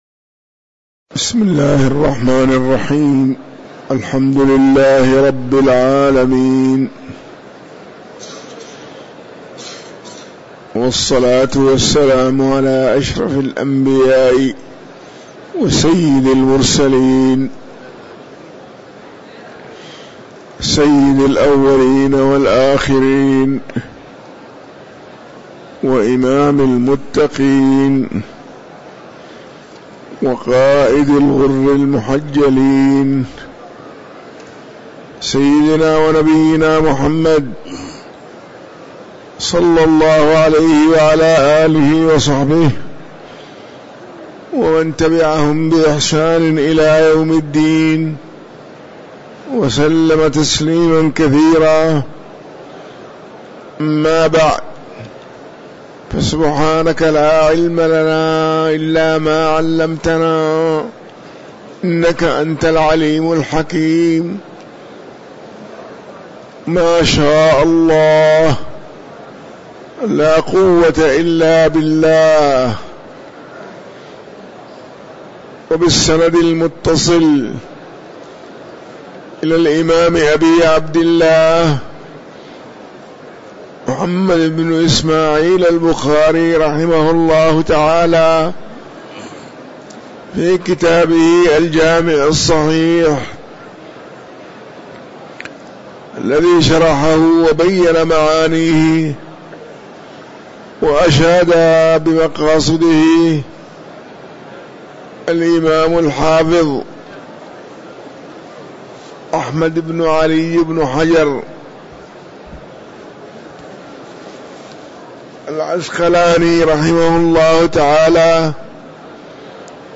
تاريخ النشر ١٥ جمادى الآخرة ١٤٤٤ هـ المكان: المسجد النبوي الشيخ